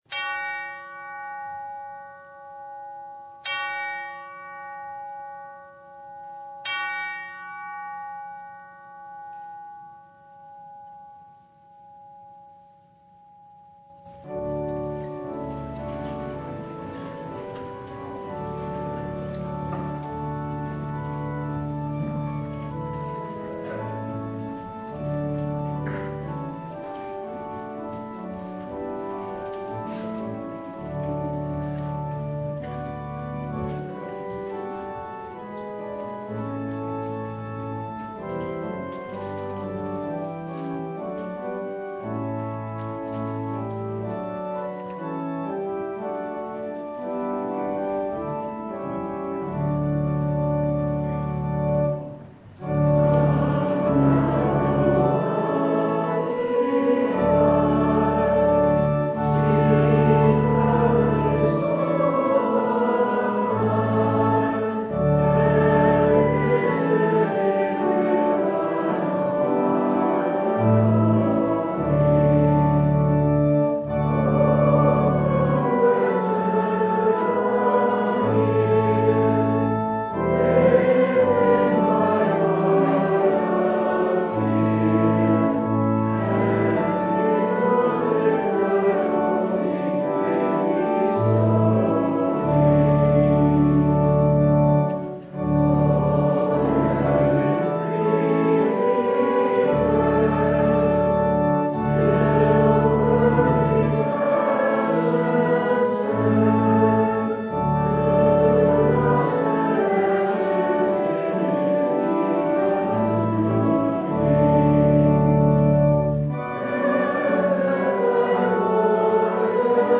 Service Type: Sunday